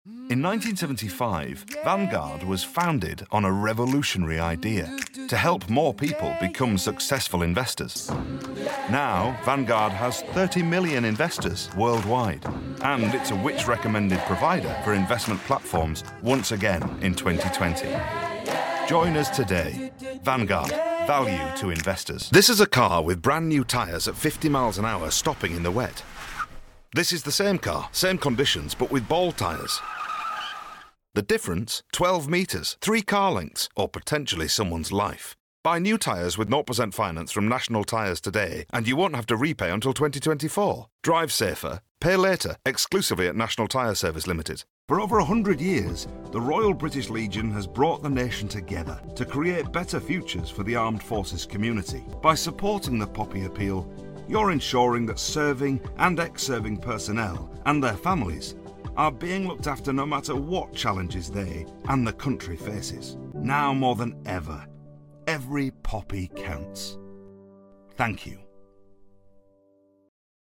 30s-40s. Deep tone. Bluff, amusing Yorkshire. Warm and friendly tone.
Commercials Narration
Native Accent: Yorkshire